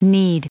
Prononciation du mot kneed en anglais (fichier audio)
Prononciation du mot : kneed